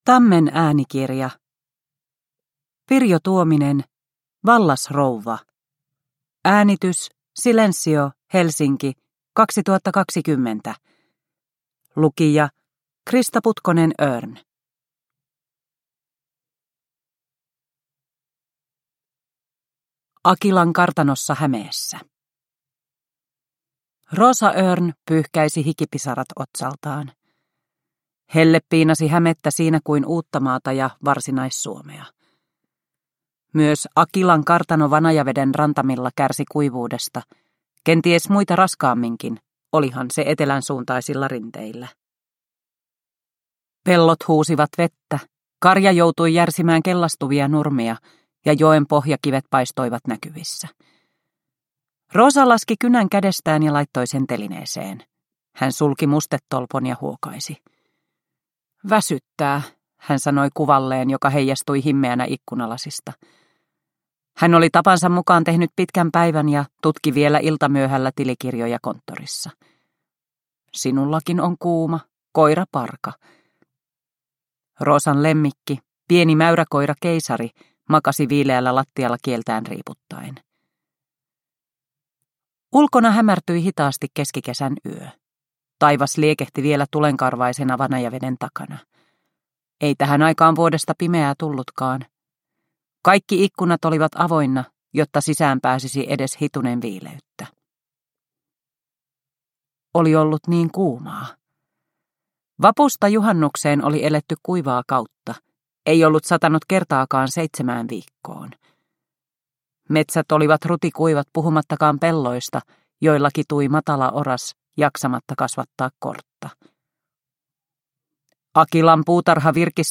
Vallasrouva – Ljudbok – Laddas ner